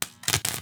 zap.wav